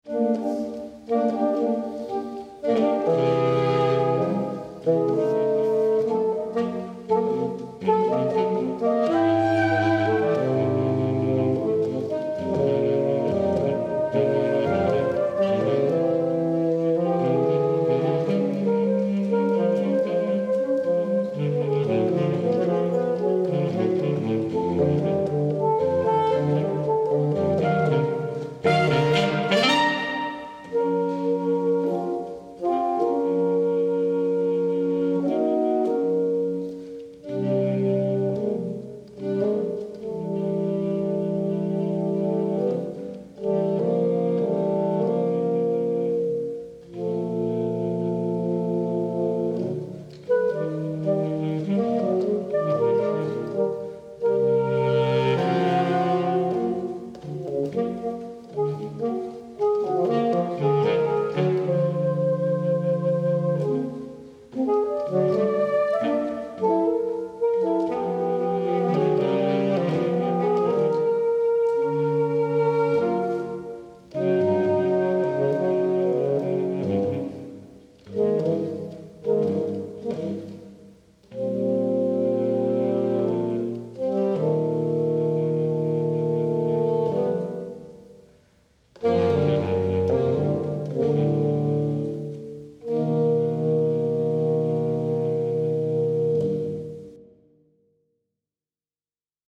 Saxophontrio